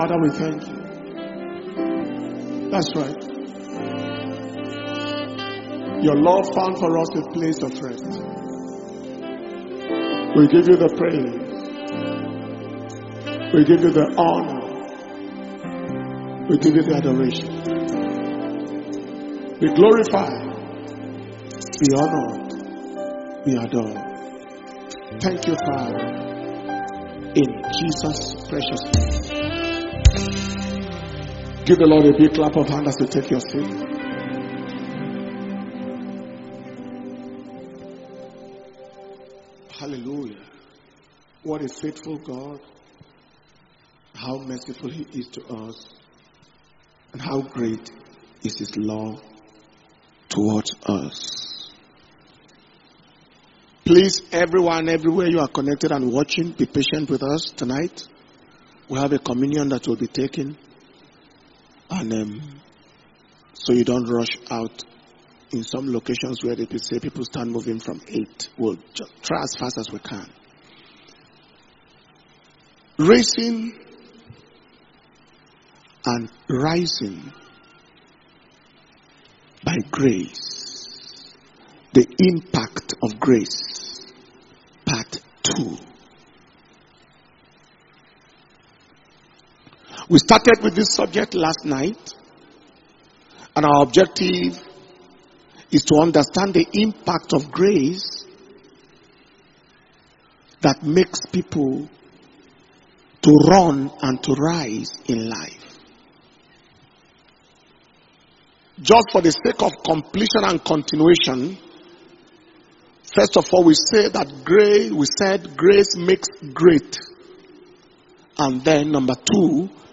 Destiny Recovery Convention 2022 – Day 2 Evening Session